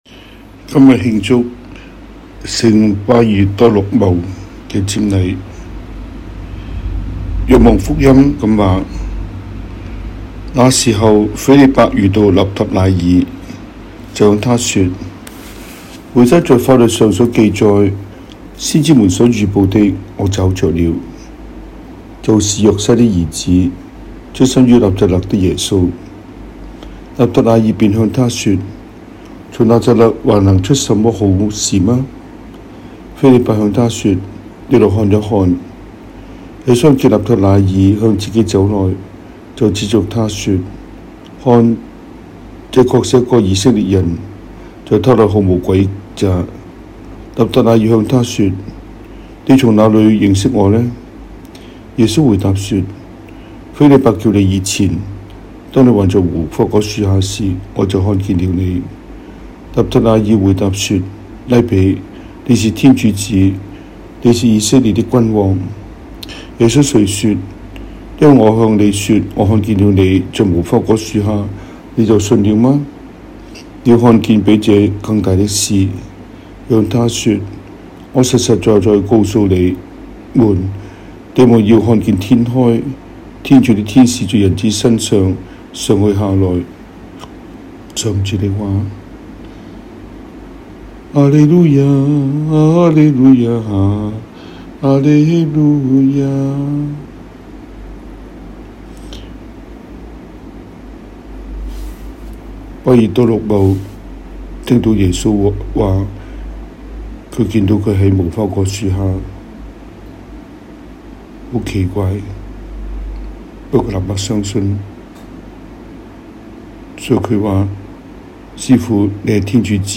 中文講道, 英文講道